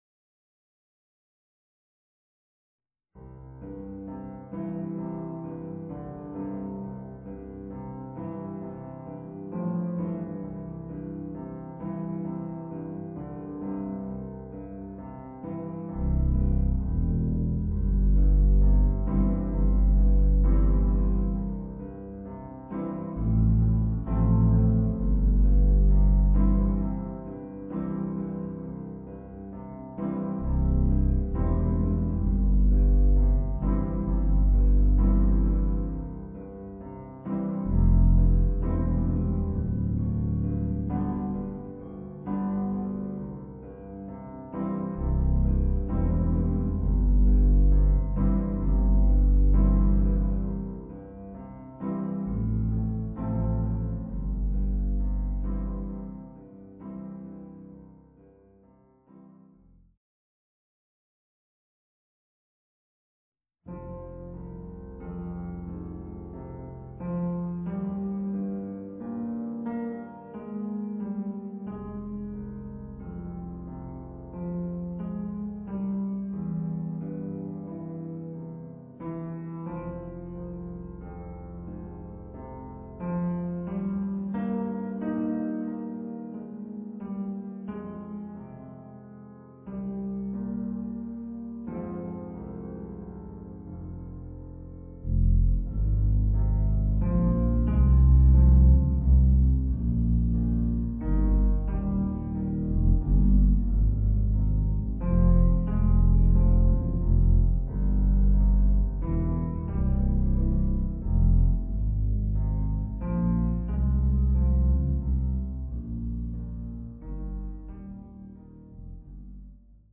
Voicing: Tuba w/ Audio